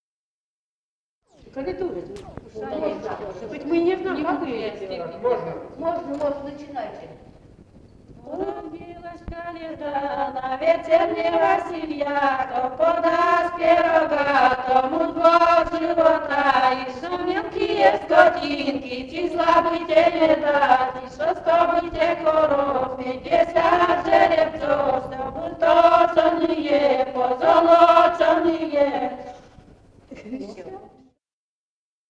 Живые голоса прошлого 019. «Уродилась Коляда» (колядка).